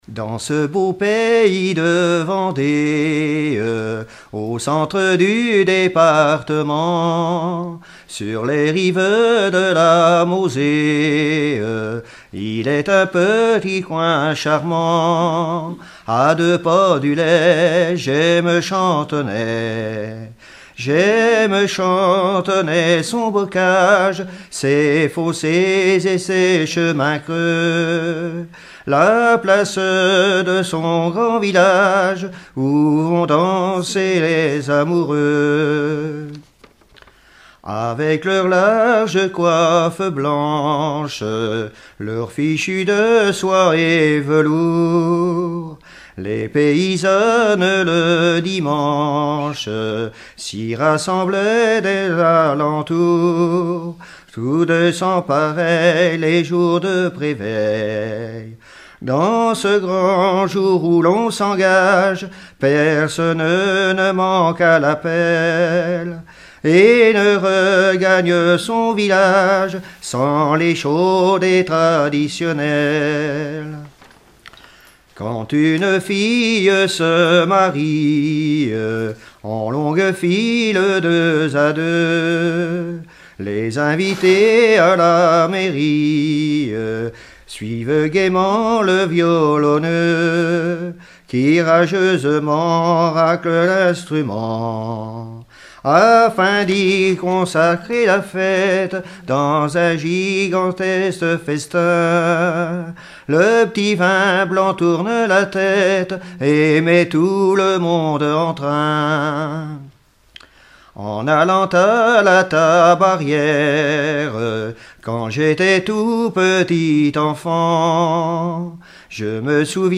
Collectif-veillée (2ème prise de son)
Pièce musicale inédite